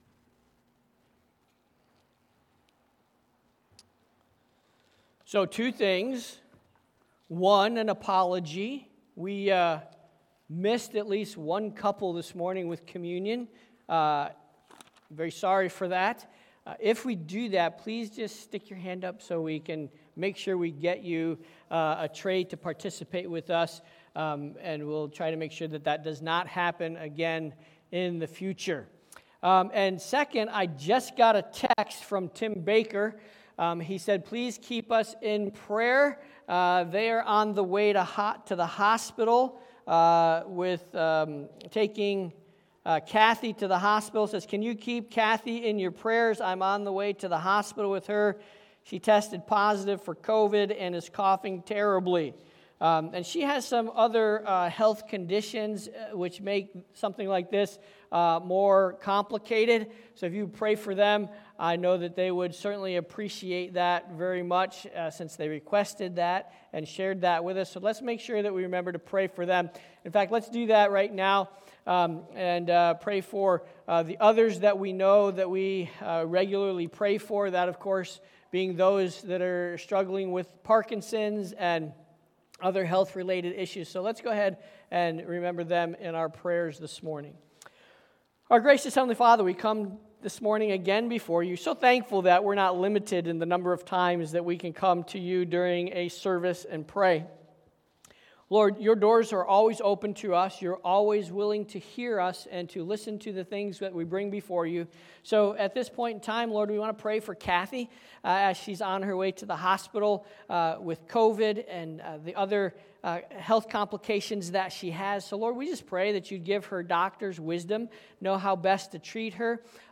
Sermons by CBCP